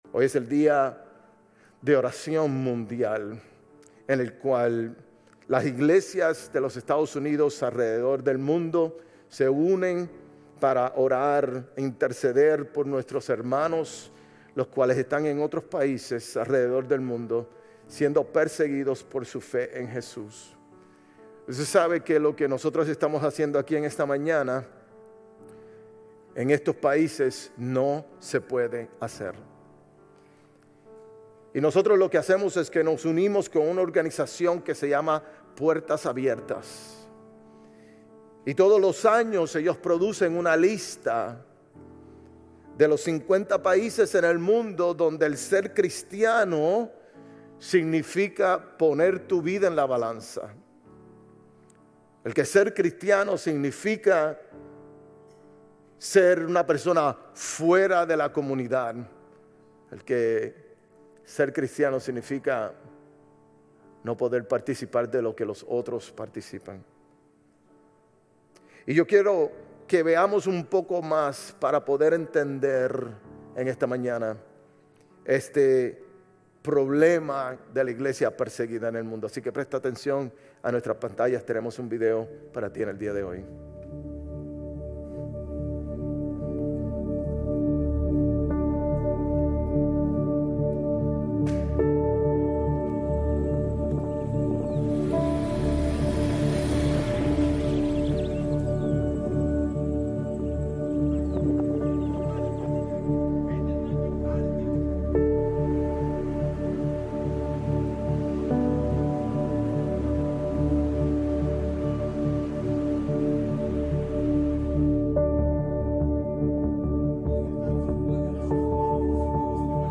Sermones Grace Español 11_2 Grace Espanol Campus Nov 03 2025 | 00:48:00 1x 00:00 / 00:48:00 Subscribe Share RSS Feed Share Link Embed